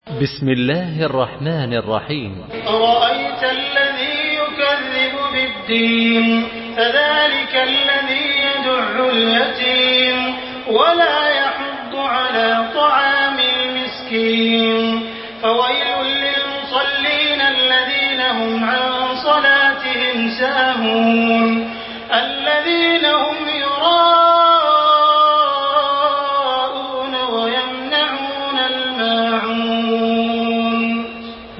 تحميل سورة الماعون بصوت تراويح الحرم المكي 1428
تراويح الحرم المكي 1428 mp3 مرتل